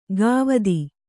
♪ gāvadi